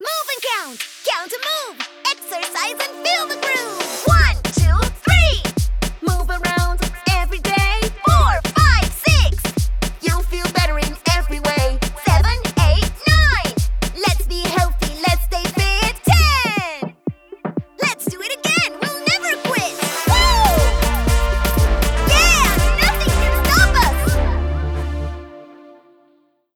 English Singing Showreel
Female
English with International Accent
Friendly